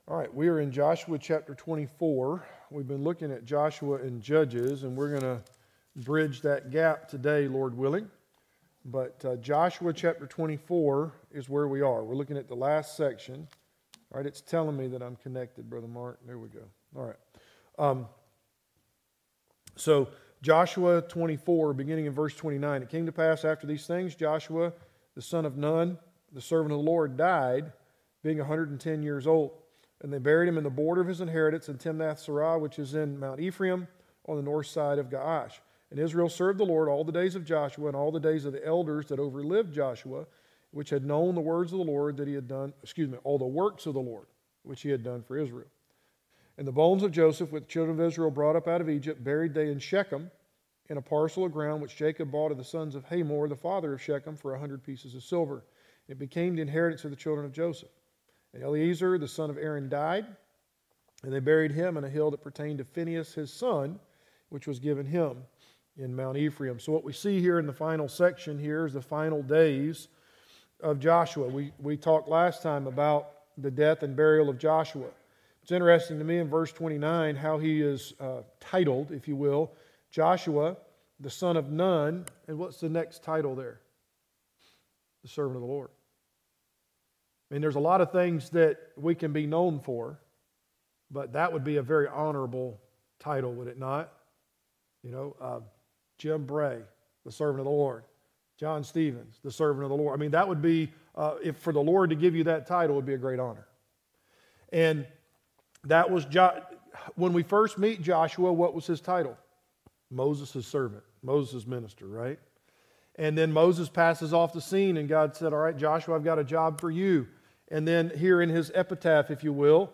Passage: Judges 1 Service Type: Adult Sunday School Class